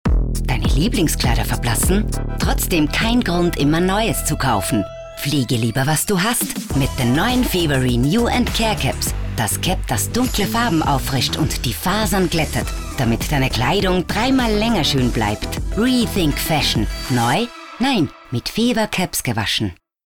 Meine Frauenstimme hat Seltenheits- bzw. Wiedererkennungswert, da ich eine tiefe, markante und seriöse Klangfarbe habe.